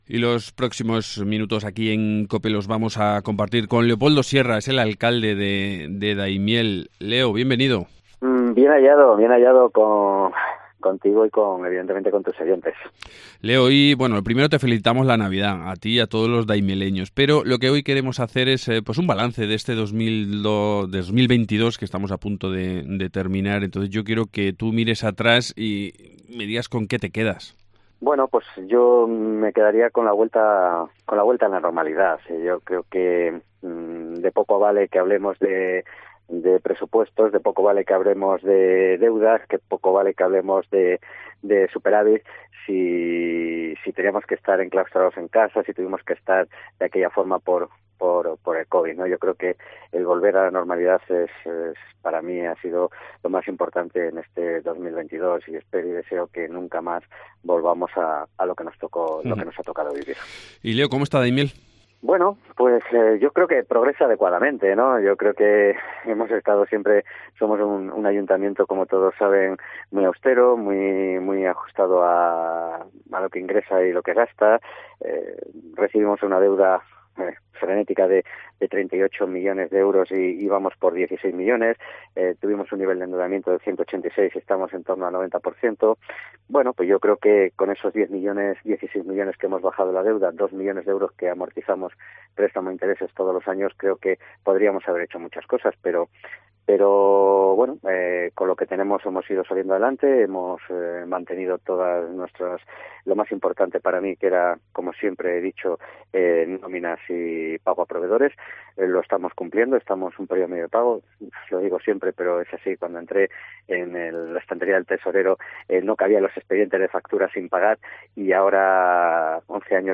Entrevista
Leopoldo Sierra, alcalde de Daimiel